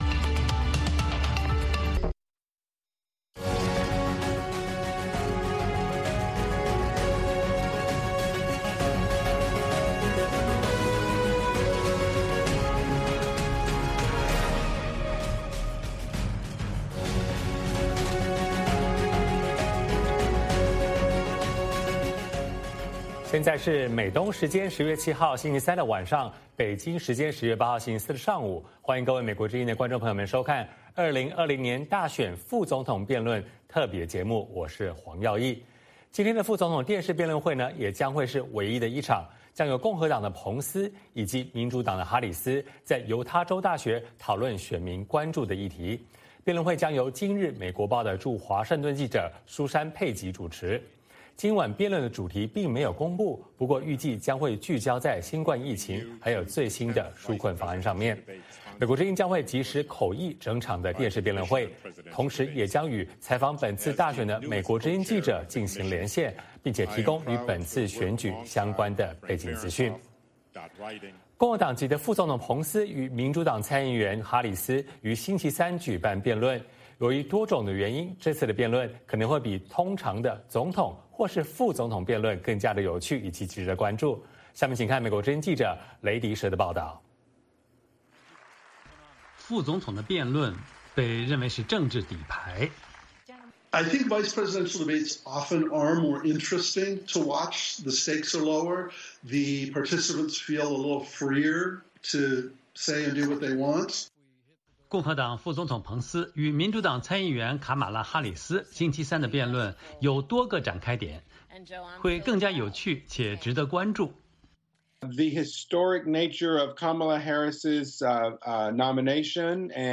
美国之音于美东时间10月7日晚间(北京时间10月8日早上)8点45分到10点45分为您直播美国大选副总统候选人电视辩论会(同声传译)，并在辩论结束后带您回顾和剖析两位候选人的中国政策和精彩的辩论瞬间。